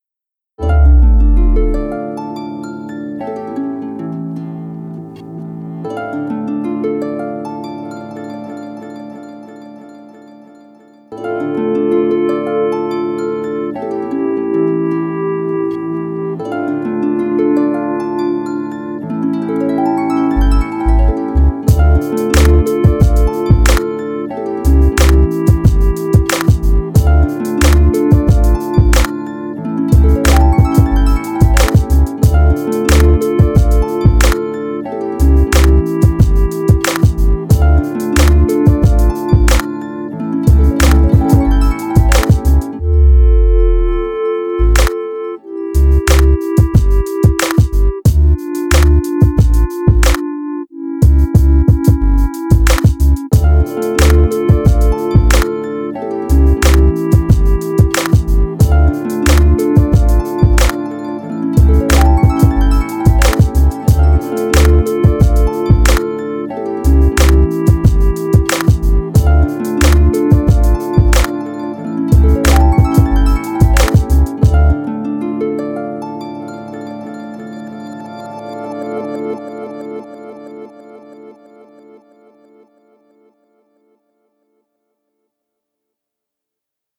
Dreamy playful harp theme and hip-hop beat.